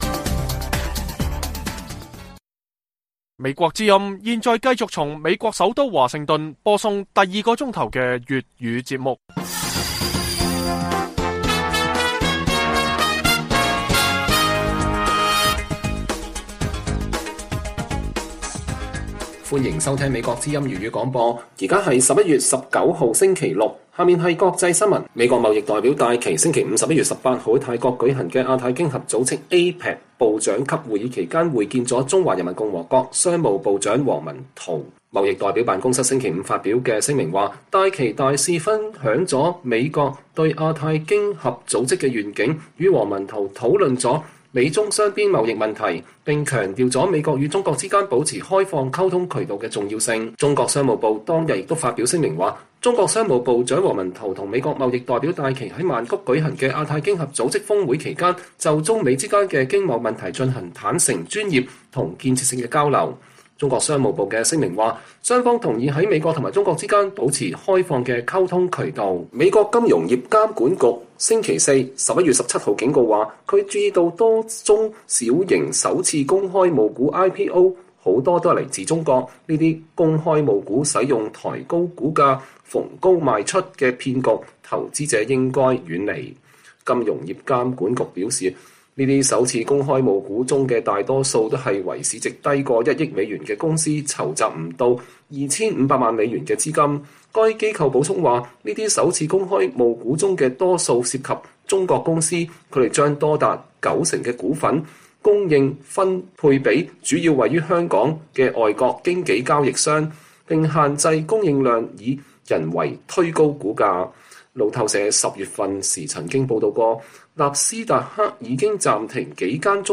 粵語新聞 晚上10-11點 : 英國政府以國安理由 指示與中國關係密切公司放售英國最大晶片廠